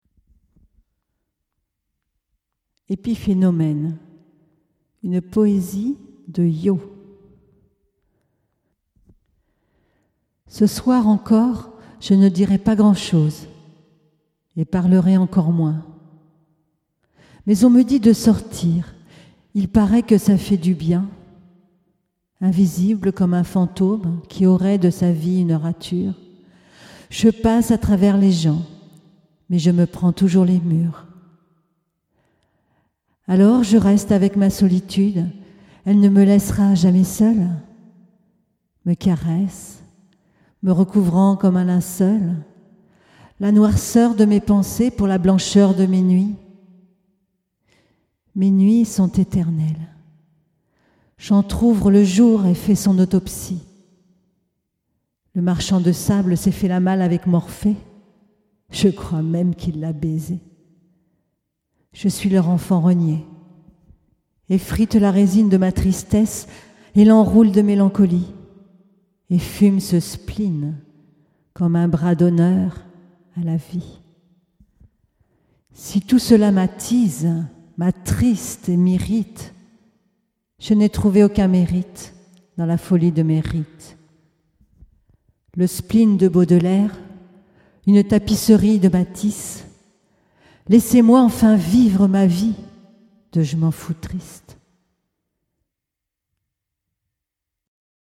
Home iPagina'Son : les textes audio Ipagina’Son ou l’apologie du spleen à l’oral…